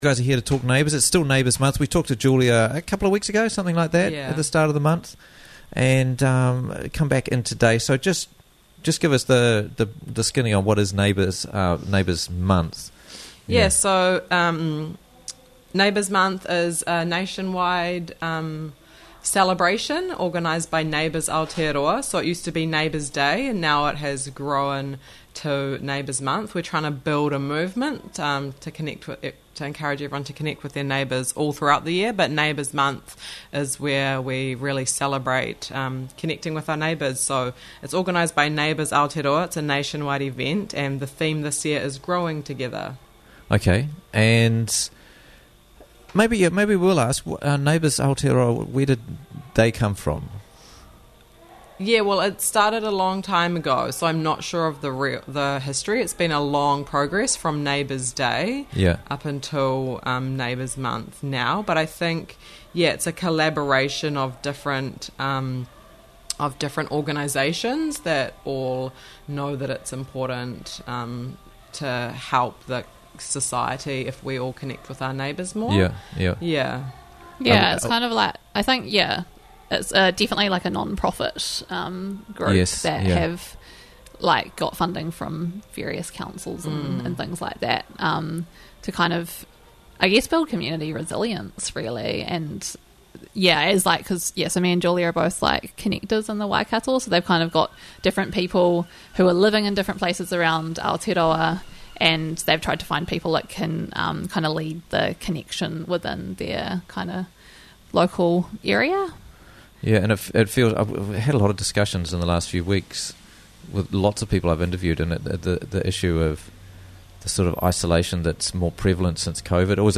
Neighbours Whaingaroa - Interviews from the Raglan Morning Show